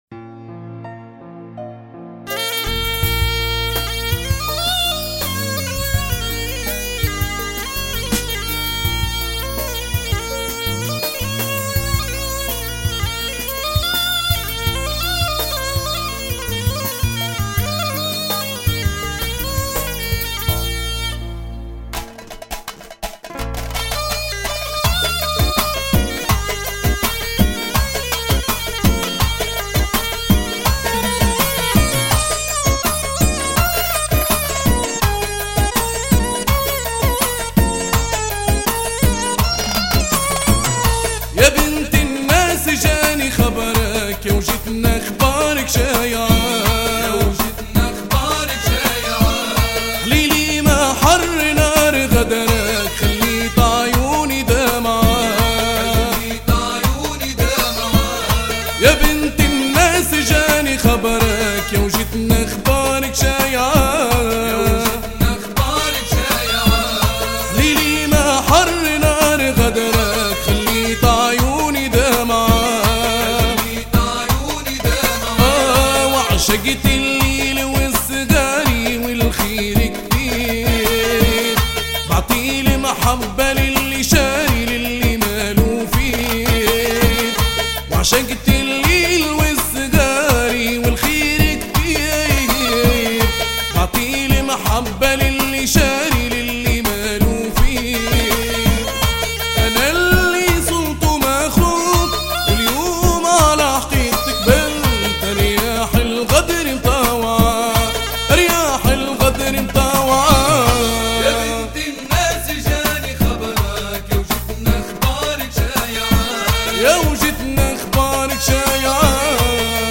Bienvenue au site des amateurs de Mezoued Tunisien
la chanson